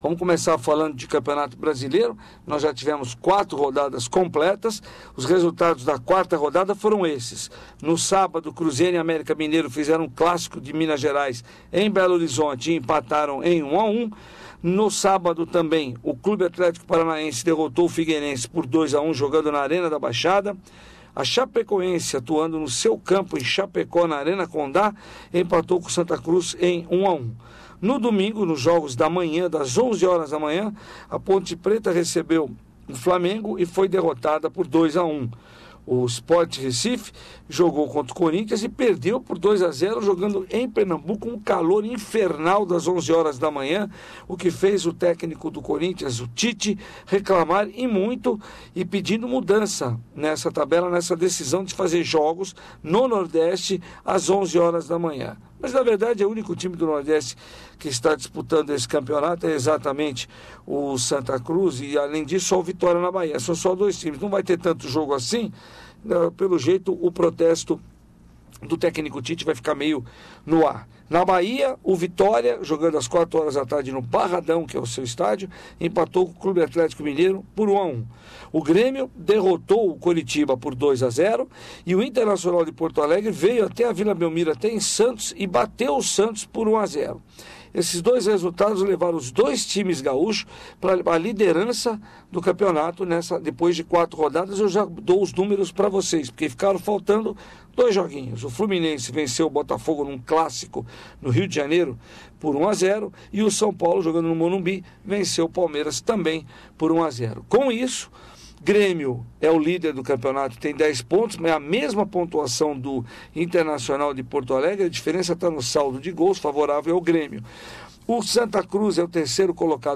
Sports bulletin